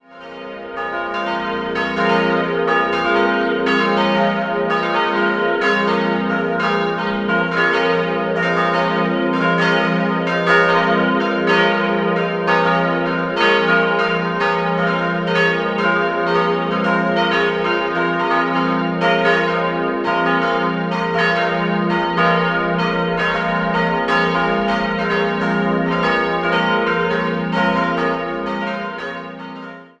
Baumeister war der Tiroler Franz Xaver Kleinhans. 4-stimmiges Geläut: f'-as'-b'-c'' Die drei kleineren Glocken wurden 1951, die große 1953 von Kuhn-Wolfart in Lauingen gegossen.